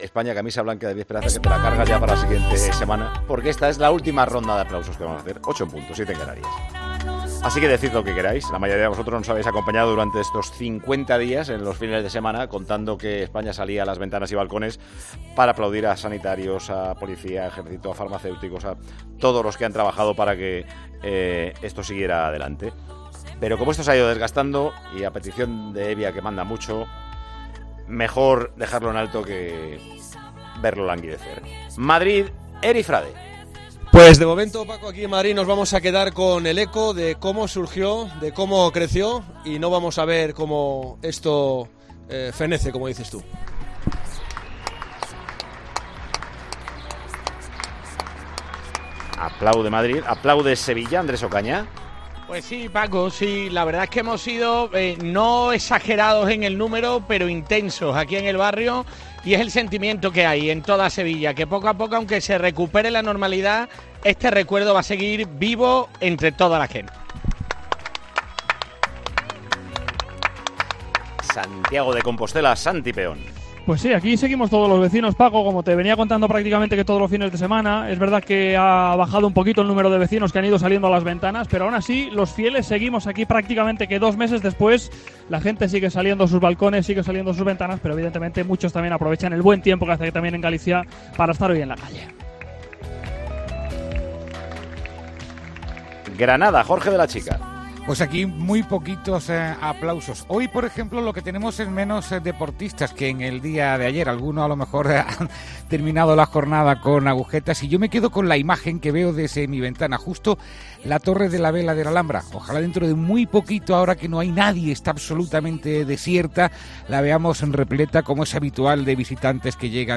Ronda de aplausos del domingo 03 de mayo de 2020
Tiempo de Juego Ronda de aplausos del domingo 03 de mayo de 2020 Suenan los balcones en el día 50 de confinamiento. Hemos querido dejar en alto los aplausos que suenan por España para todo el personal sanitario en primera línea.